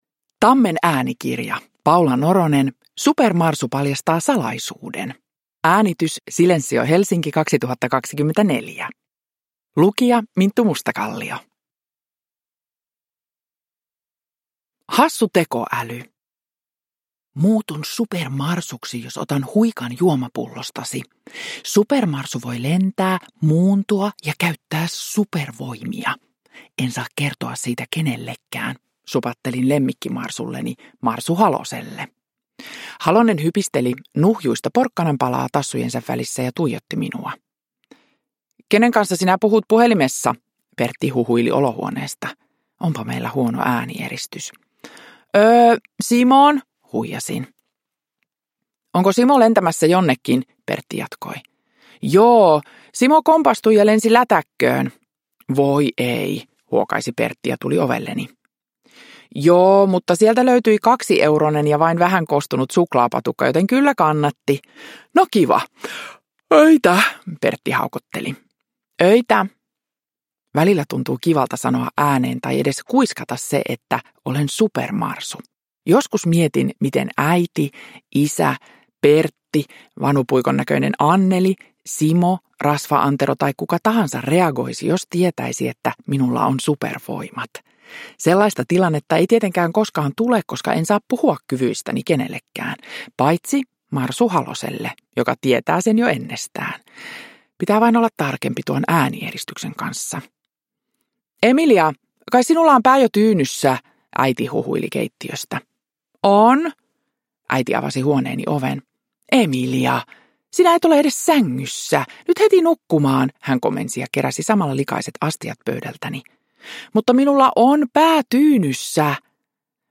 Supermarsu paljastaa salaisuuden – Ljudbok
Uppläsare: Minttu Mustakallio